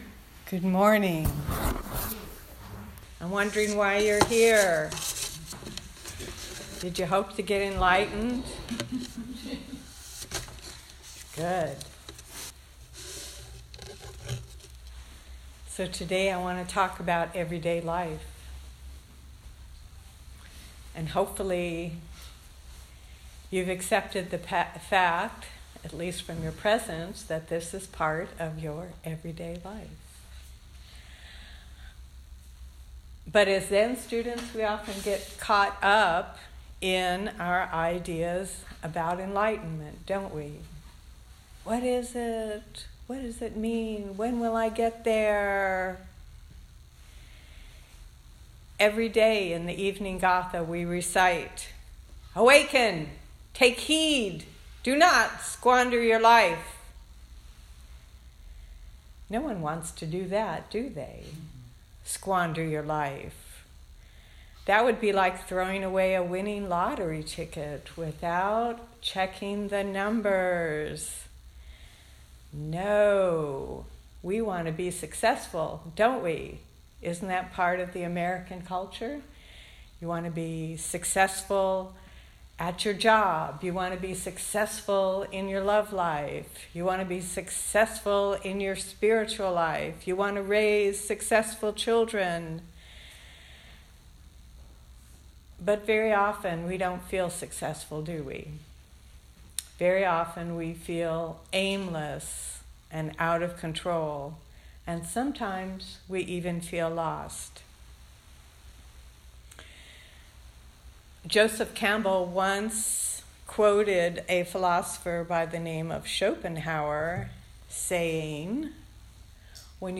Dharma Talk
September 22 2018 Southern Palm Zen Group